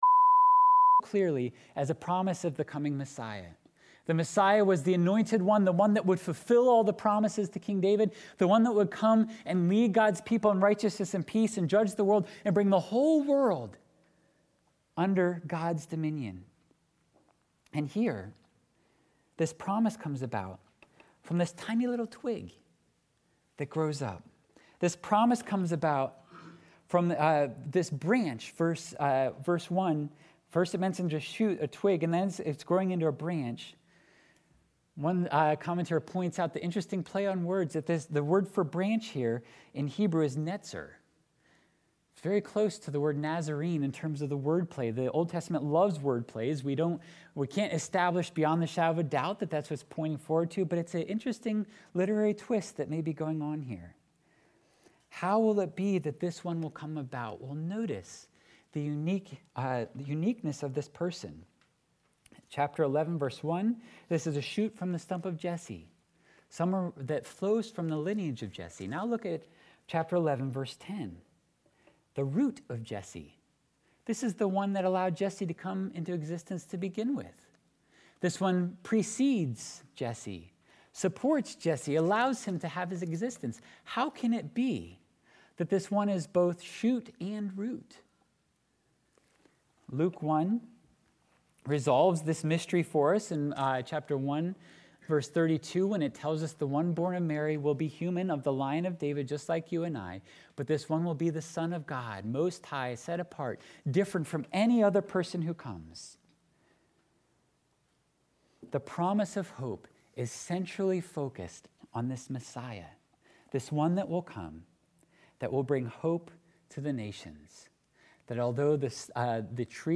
Sunday Worship – December 19, 2021 – The Peaceable Kingdom